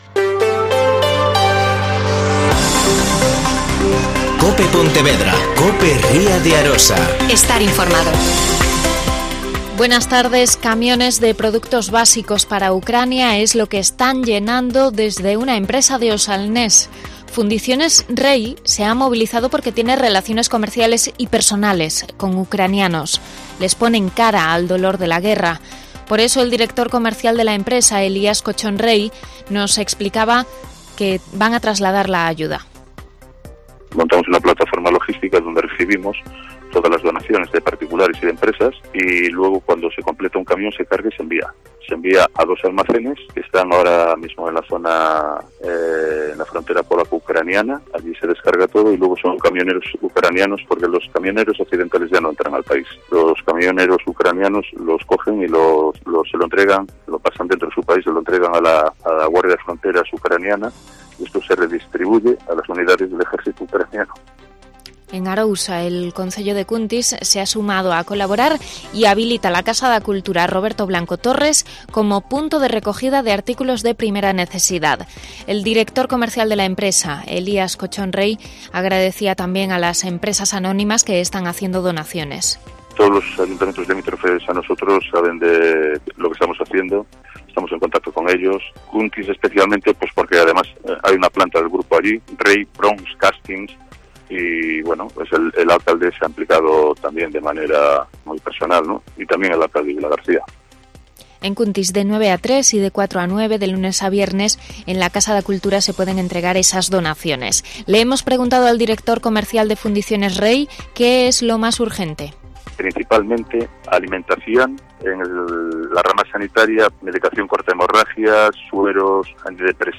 Mediodía COPE Pontevedra y COPE Ría de Arosa (Informativo 14:20h.)